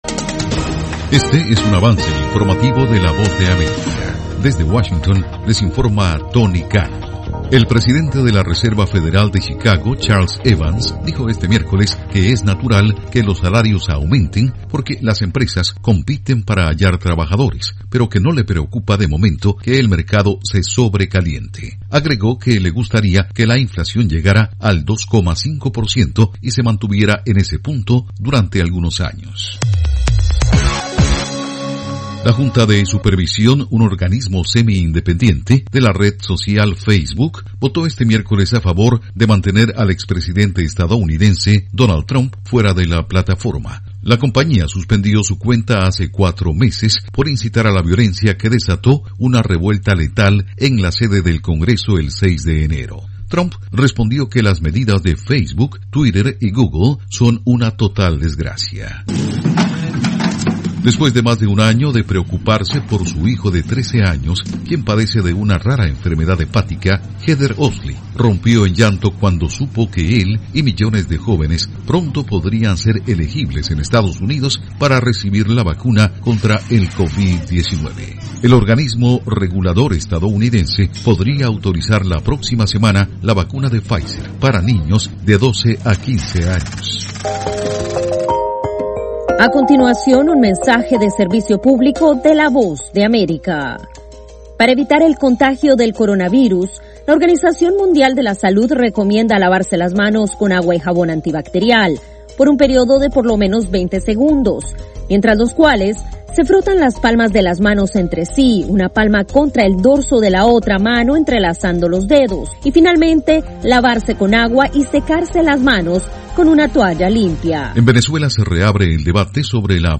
Cápsula informativa de tres minutos con el acontecer noticioso de Estados Unidos y el mundo